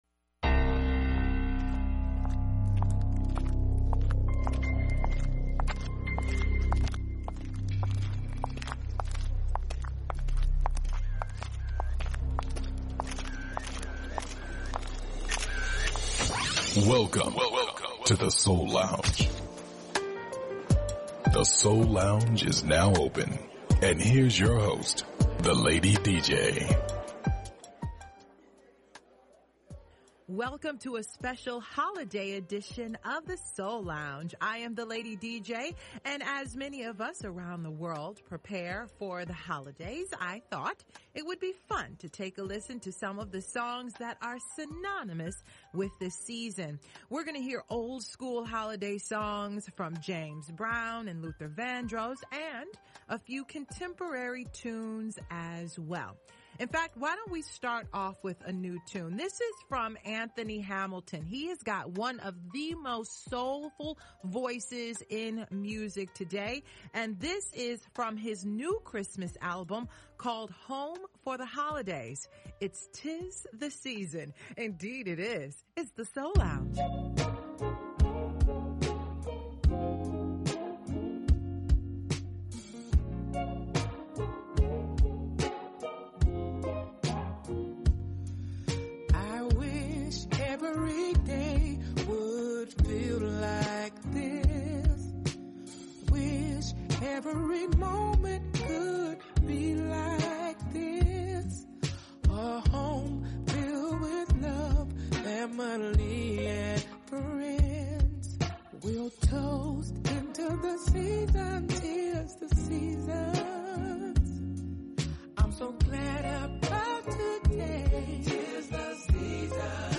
Neo-Soul
conscious Hip-Hop
Classic Soul